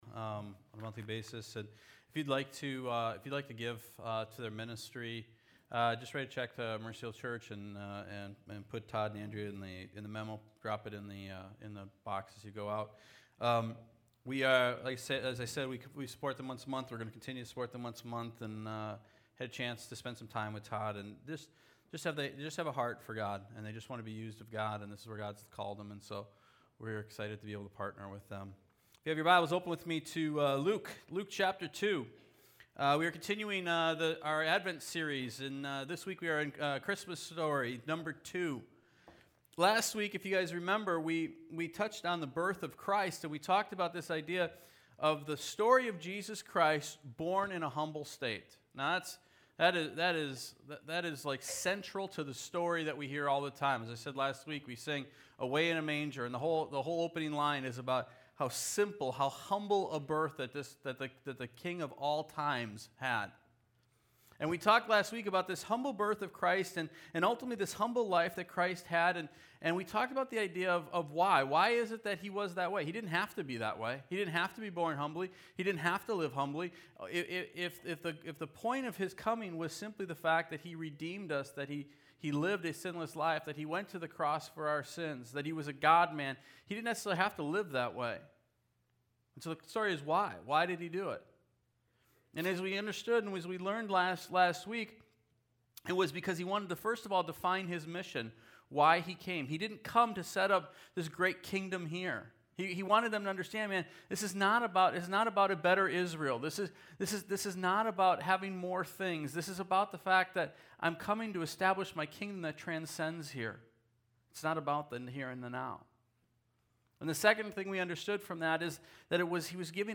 A King Is Born — Bay View Sermons — Mercy Hill Church